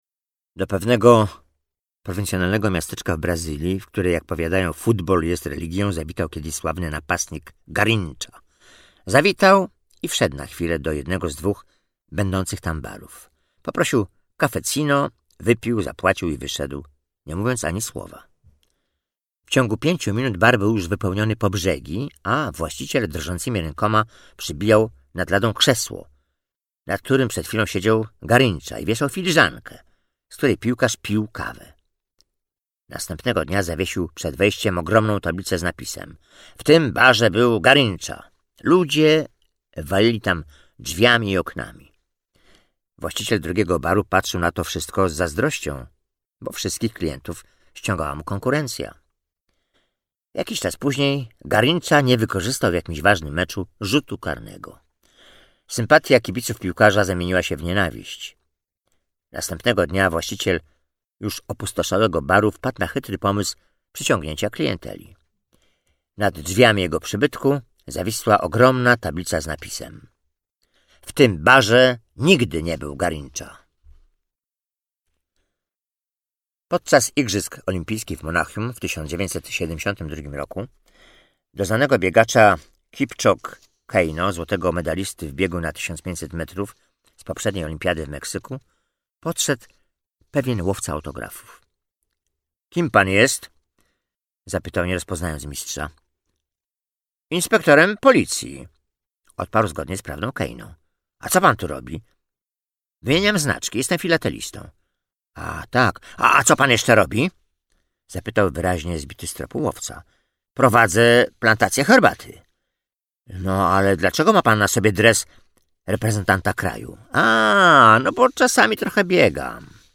Najlepsze anegdoty o sportowcach - Audiobook mp3
Lektor